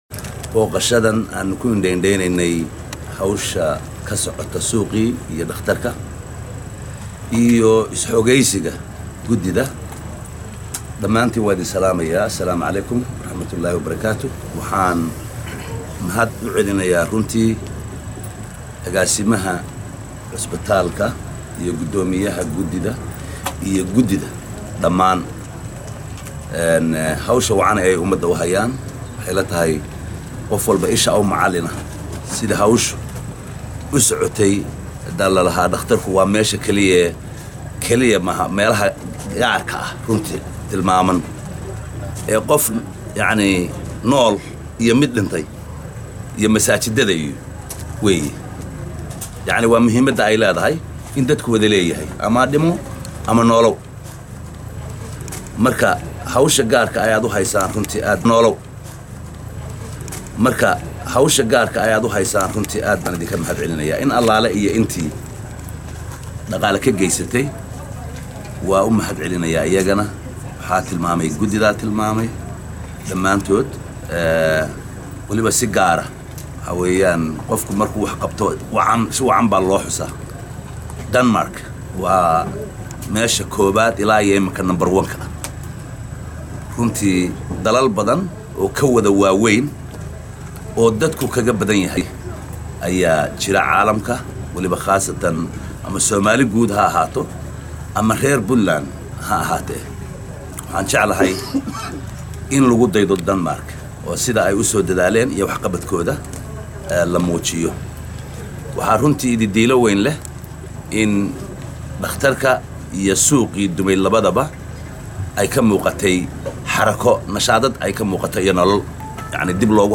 Dhagayso: Madaxweyne ku xigeenka Puntland oo ka hadlay Arimaga Gobolka Mudug.
Madaxweyne ku xigeen Dowladda Puntland ayaa waxaa uu booqday, Suuqa weyn ee Xero dayax iyo Isbitaalka weyn ee Gobolka Mudug, isaga oo ka dibna Saxaafadda La hadlay.
Mar uu arinta Isbitaalka ka Hadlayey Wuxuu yiri.